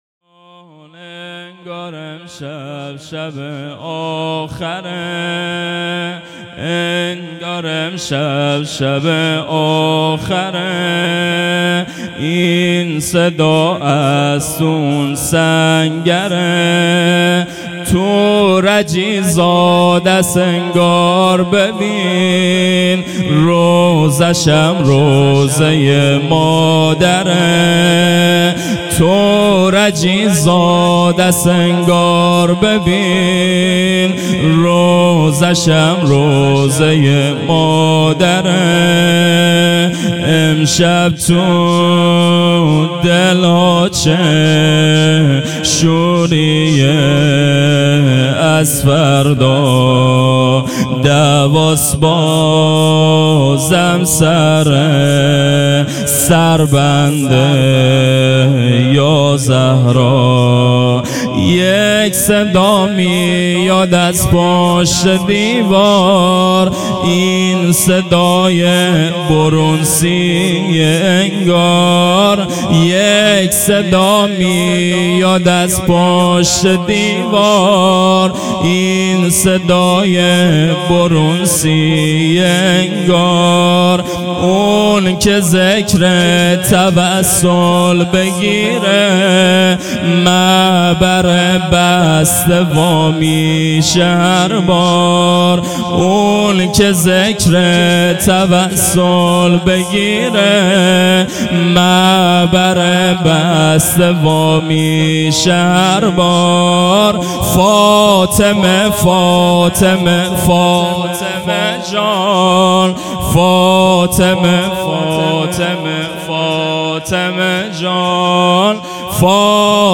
مداحی شهدایی